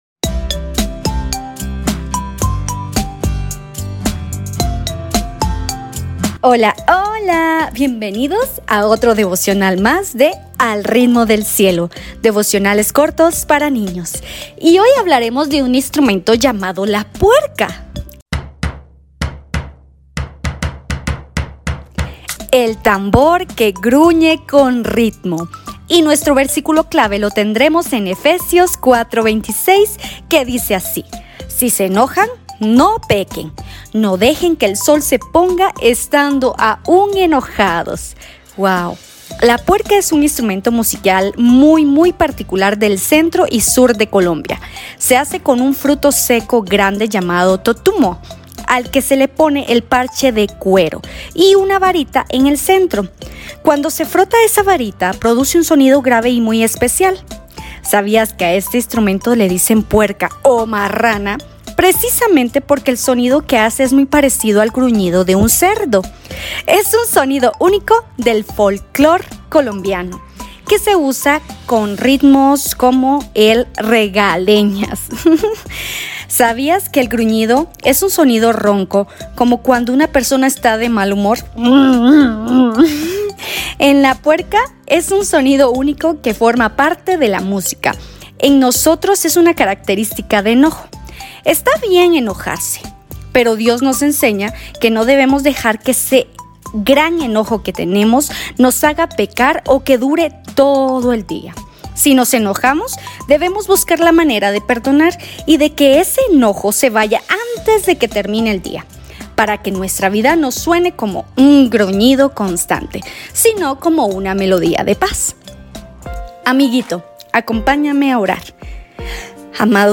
¡Al Ritmo del Cielo! – Devocionales para Niños